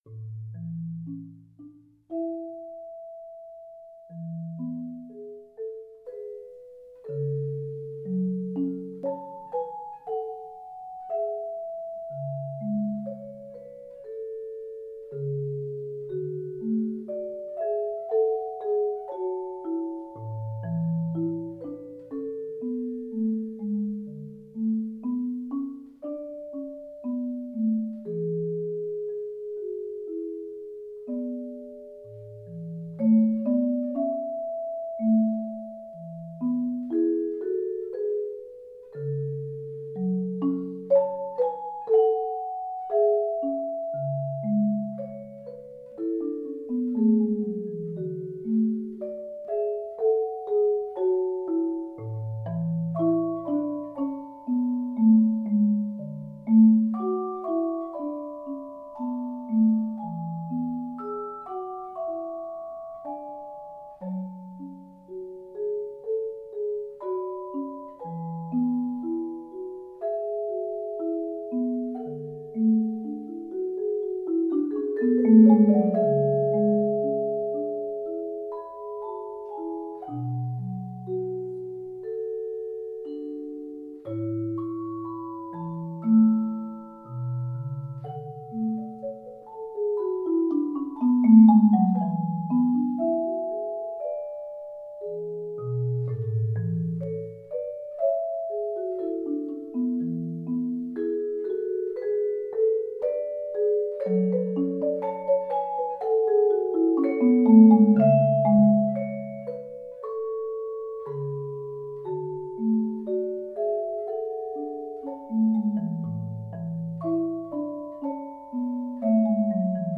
Genre: Duet for Vibraphone & Marimba
thoughtful duet for marimba and vibraphone
Vibraphone
Marimba [4.3-octave]